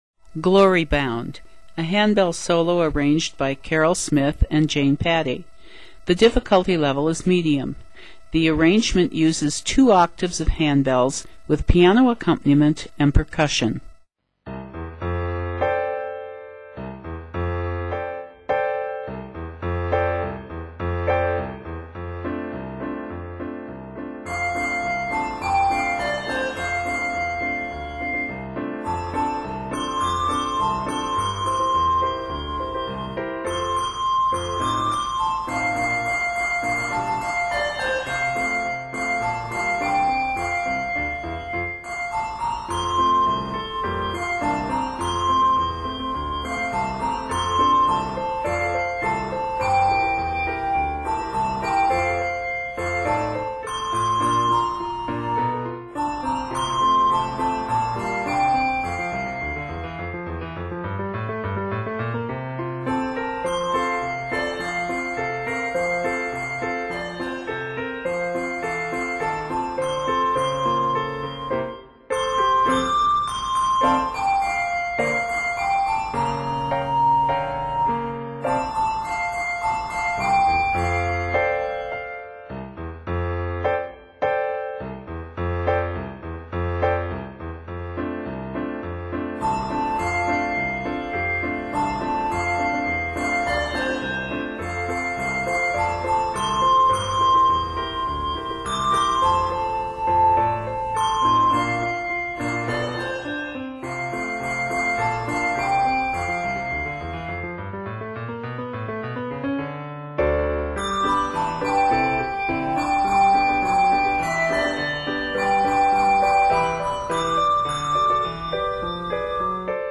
Solo Ringer
Spiritual
Voicing: Handbells, No Choral Instrument: Percussion , Piano